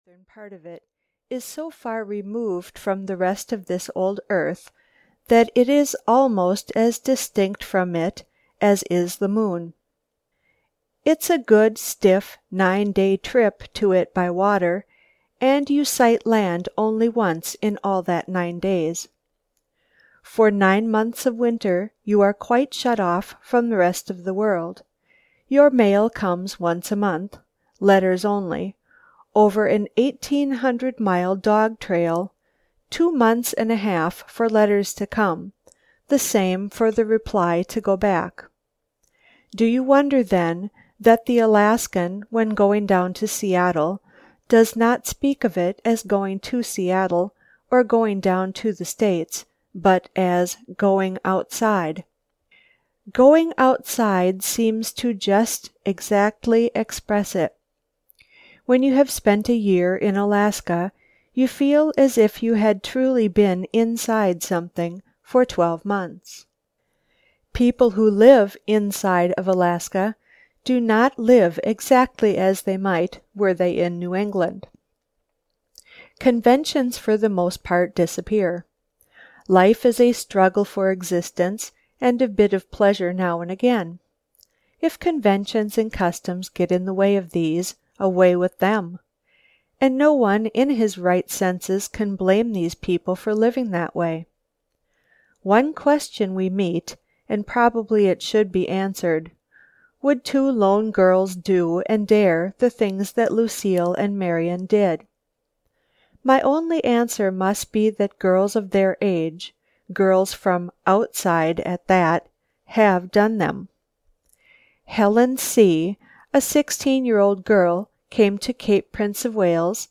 The Blue Envelope (EN) audiokniha
Ukázka z knihy